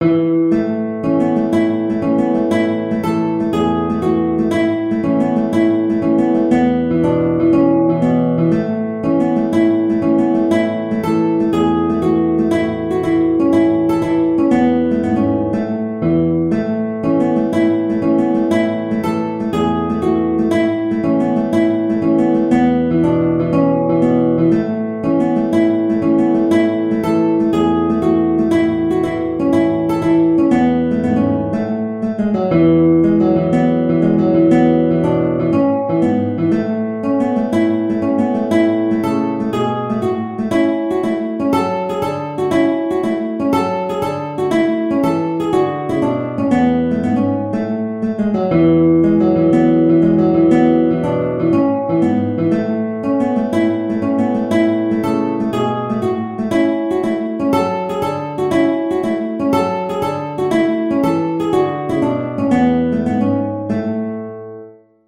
Guitar version
4/4 (View more 4/4 Music)
E3-A5
Guitar  (View more Intermediate Guitar Music)